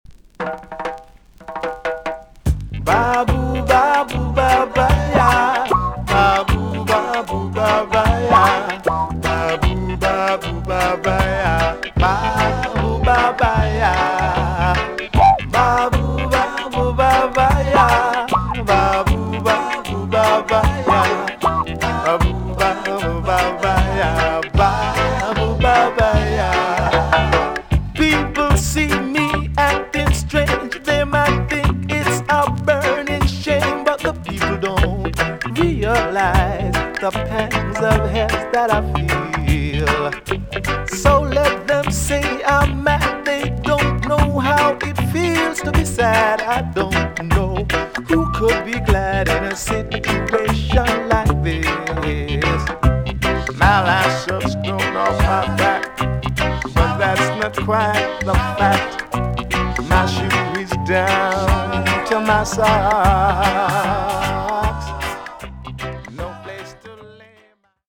TOP >LP >VINTAGE , OLDIES , REGGAE
B.SIDE EX- 音はキレイです。